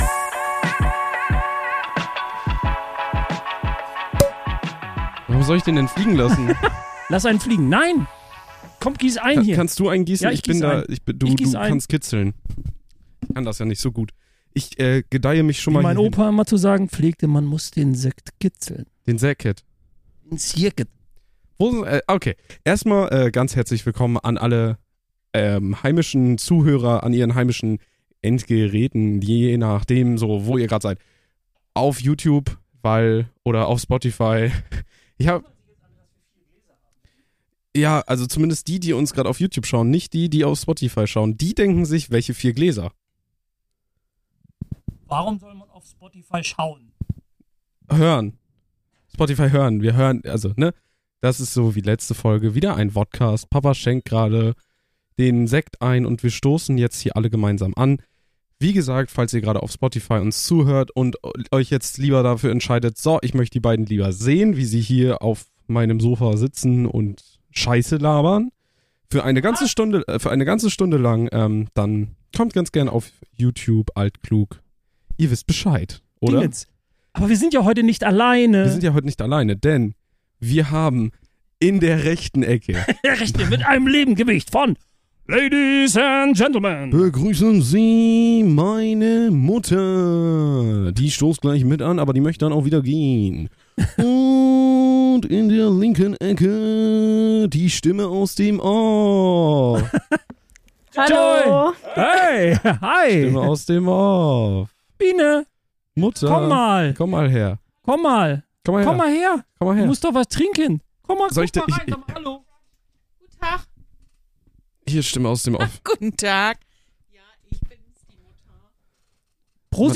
Start ins neue Jahr, mit einem Vodcast und Stimmen aus dem Off.